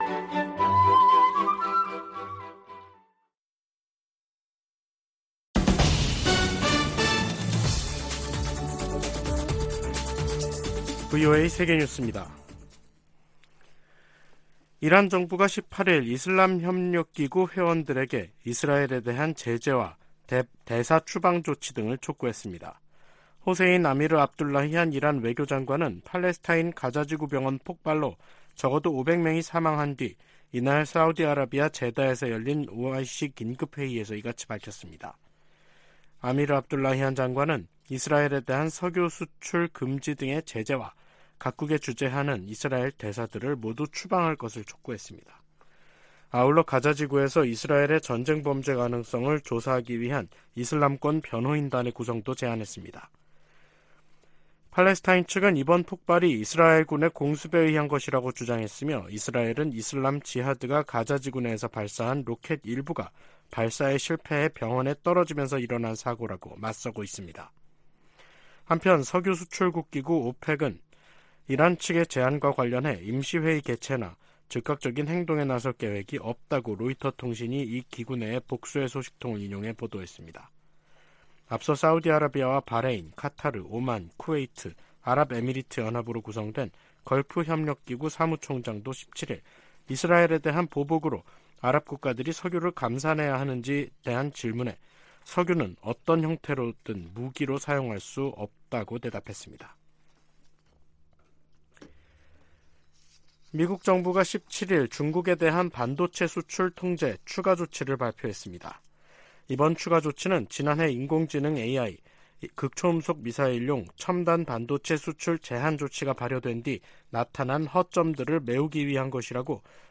VOA 한국어 간판 뉴스 프로그램 '뉴스 투데이', 2023년 10월 18일 3부 방송입니다. 미 국무부가 러시아 외무장관 방북과 관련해 러시아가 첨단 군사기술을 북한에 넘길 것을 우려하고 있다고 밝혔습니다. 미 인도태평양사령관은 북한과 러시아 간 무기 거래 등 최근 움직임으로 역내 위험성이 커졌다고 지적했습니다. 팔레스타인 무장 정파 하마스가 가자지구에서 북한제 무기를 사용한다고 주한 이스라엘 대사가 VOA 인터뷰에서 말했습니다.